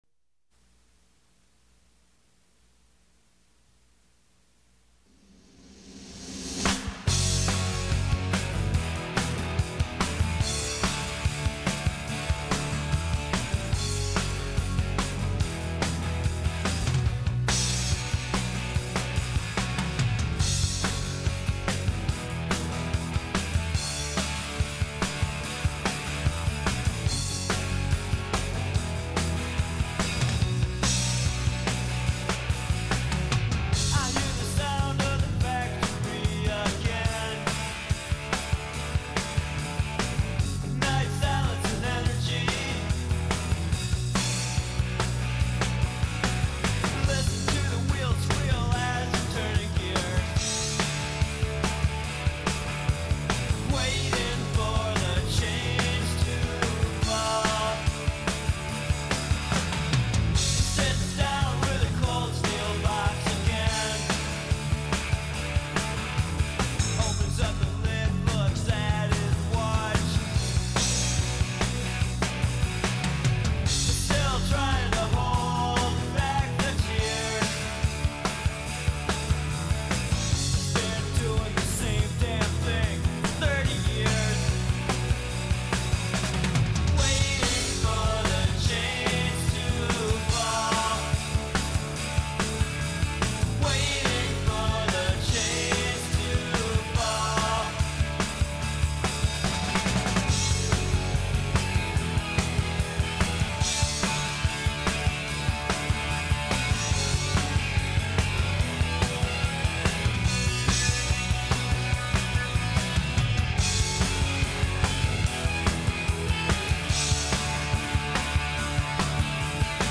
Midwest factory music